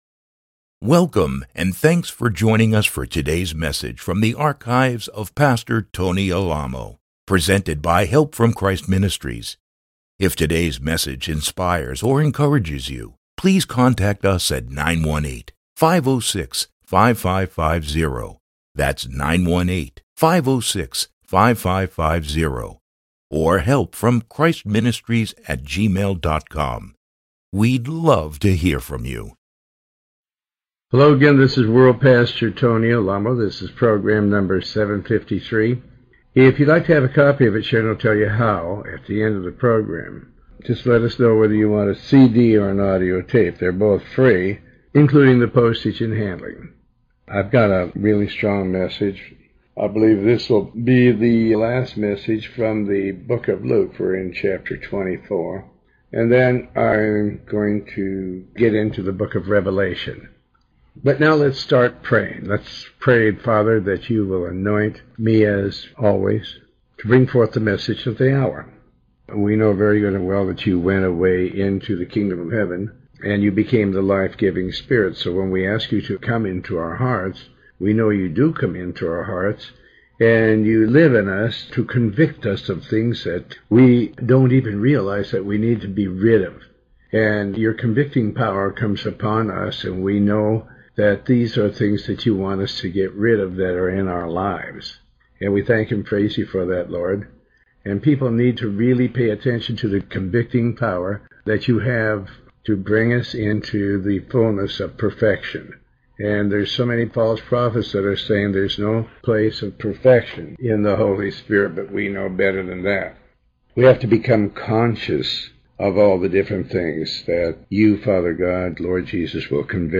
Sermon 753A